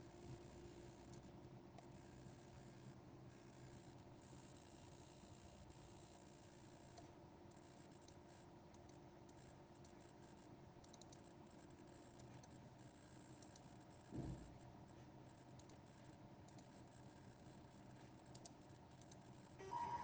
Nicht definierbare Störgeräusche IM PC beim bewegen oder Scrollen mit der Maus
Deshalb hab ich es mit dem Handy aufgenommen und als WAV hochgeladen. Dieses Geräusch ist absolut seltsam und beim Arbeiten am PC so störend das innerliche Aggressionsprobleme erscheinen Wenn ich ins BIOS Starte und dort mit dem BIOS interagiere ist das Phänomen nicht vorhanden.